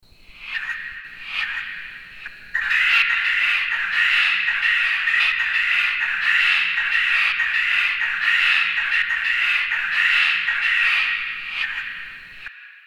Codorniz japonesa (Coturnix japonica)